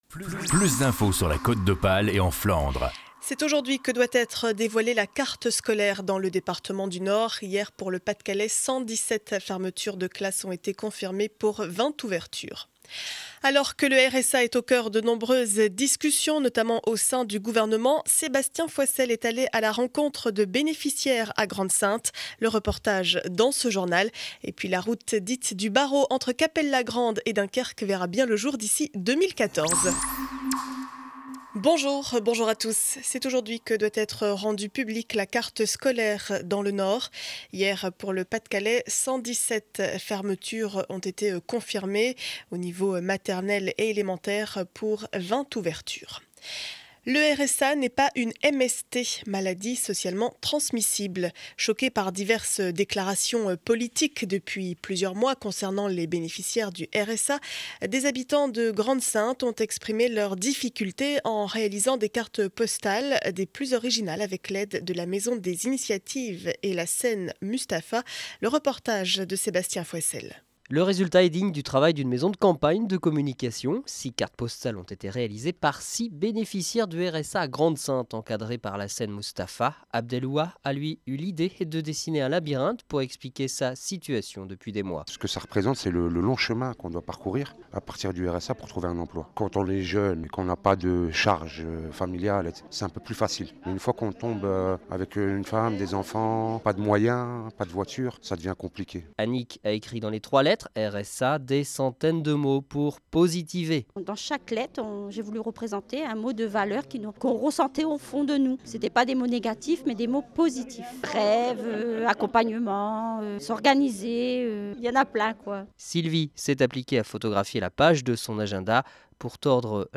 Journal du vendredi 24 février 2012 7 heures 30 édition du Dunkerquois.